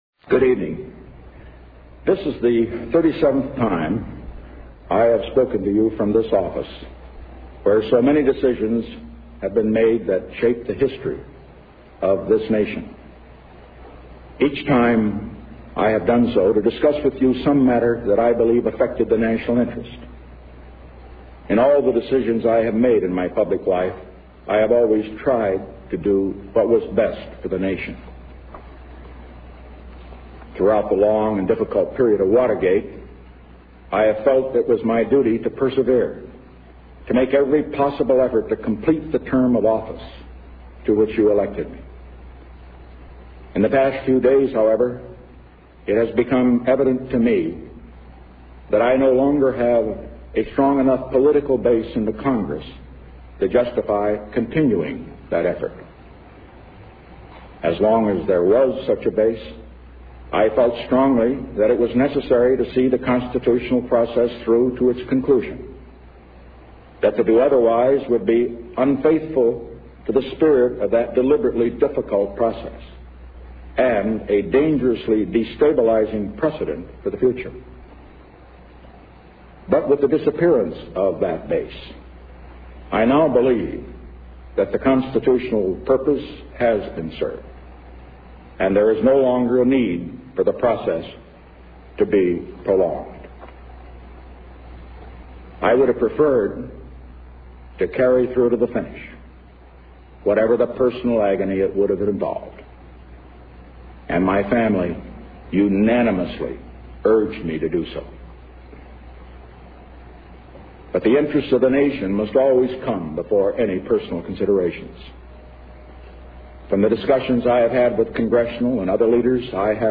Resignation Address to the Nation